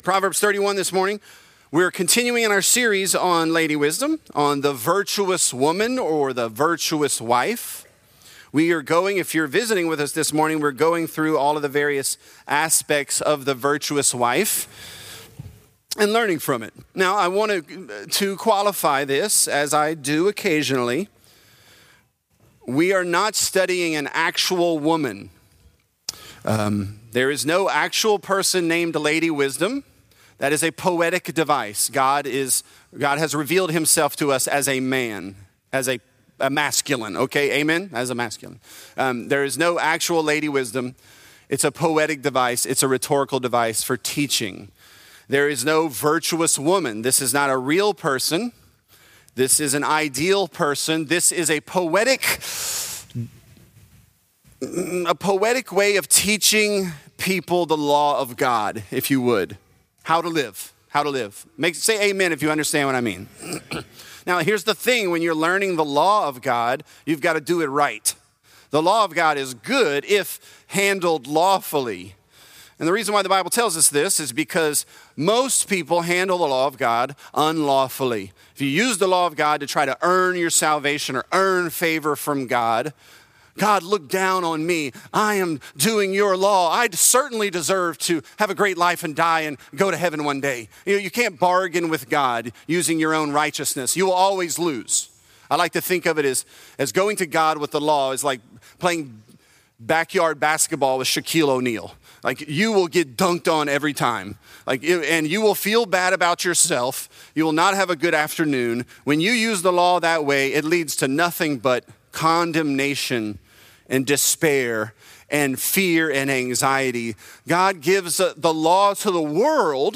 Virtuous: She Makes Her Arms Strong | Lafayette - Sermon (Proverbs 31)